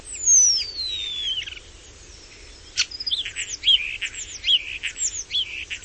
Sturnus vulgaris
In genere è molto rumoroso in particolare negli stormi. àˆ un eccellente imitatore, non solo di altri uccelli, ma anche di animali domestici o di rumori del traffico. La nota più comune è un raspato ‘tciir’ e un chiaro ‘piioo’ in calando.
Storno-Sturnus-vulgaris.mp3